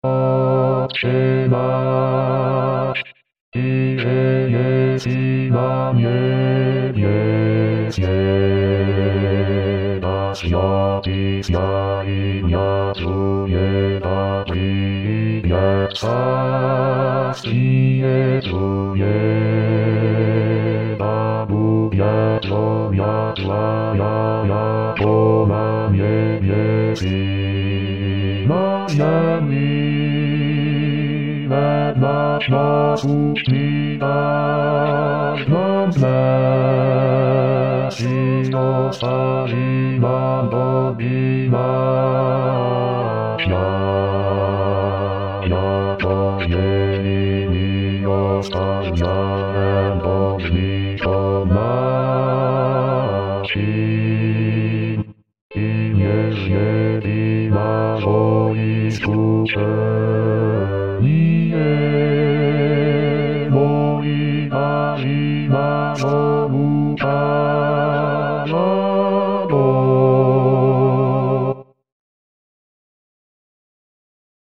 Basse.mp3